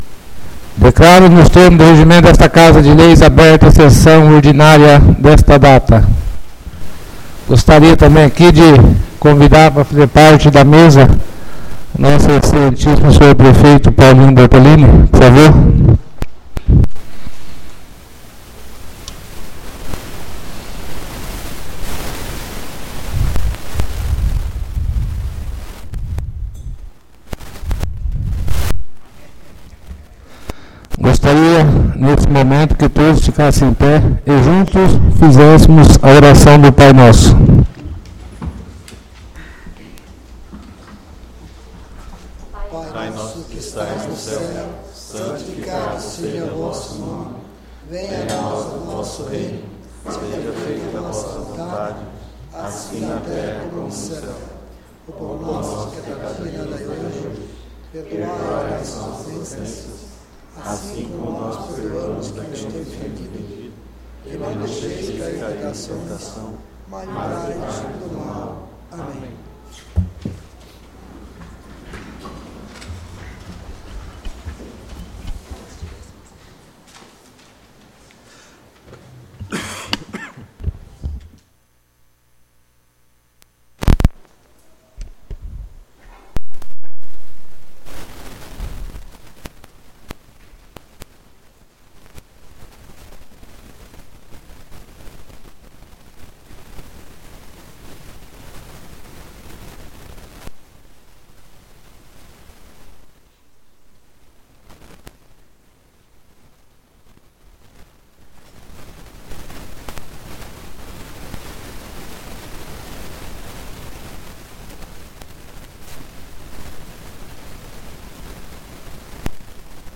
ÁUDIO SESSÃO 06-06-22 — CÂMARA MUNICIPAL DE NOVA SANTA HELENA - MT